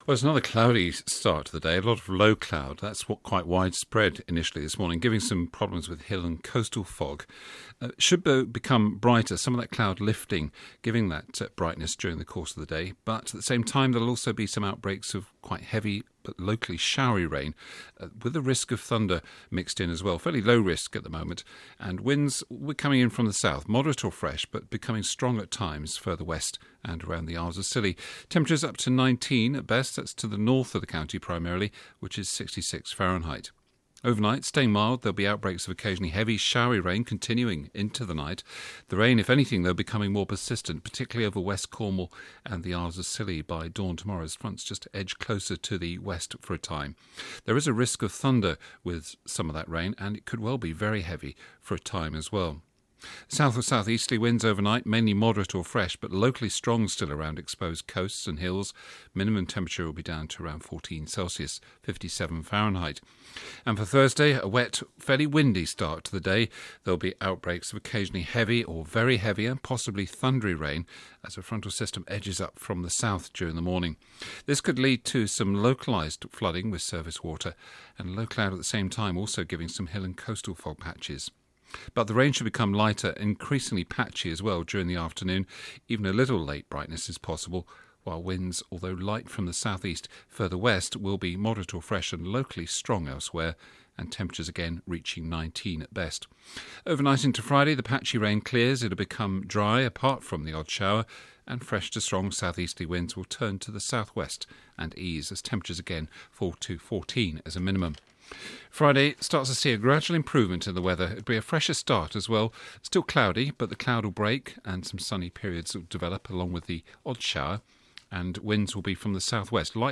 5 day forecast for Cornwall and Scilly from 8.15AM on 2 October